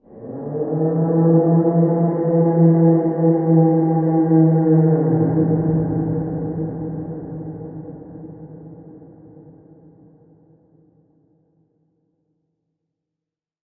Звуки страшных голосов
Зов существа из подвального мрака